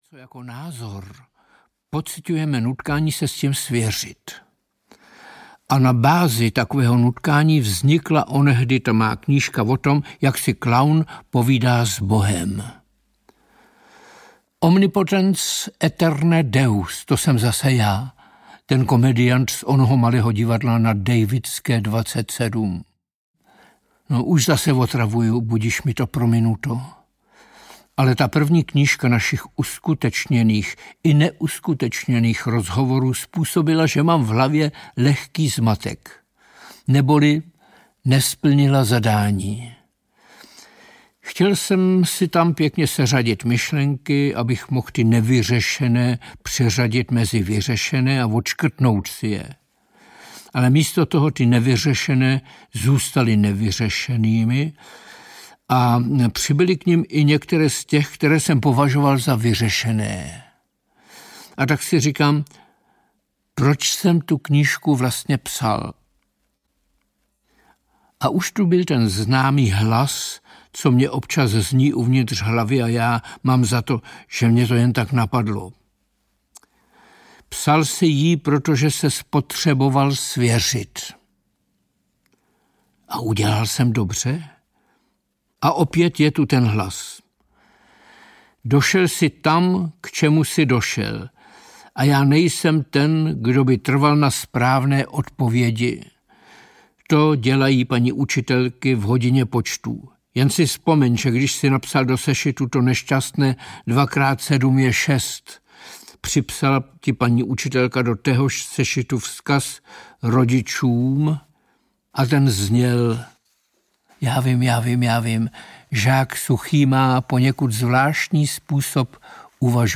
Ukázka z knihy
I tuto knihu Jiří Suchý osobně namluvil jako audioknihu.